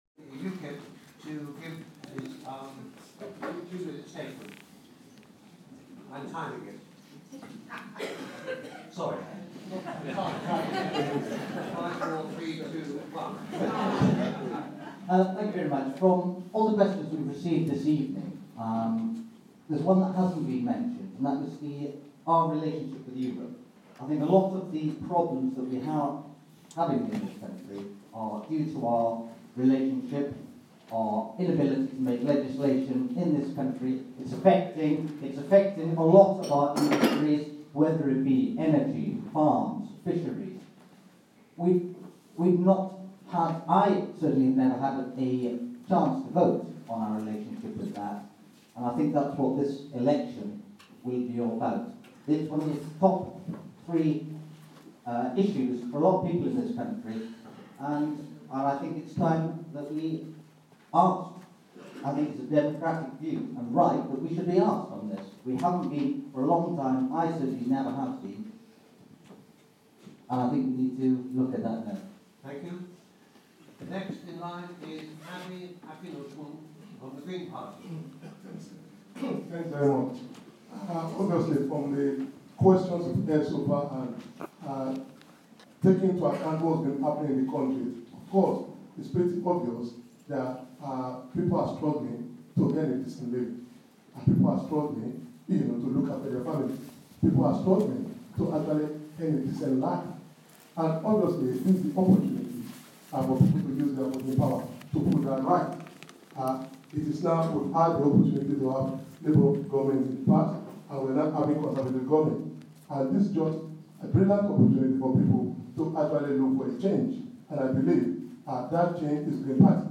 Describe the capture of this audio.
Mycenae House hustings - most closing speeche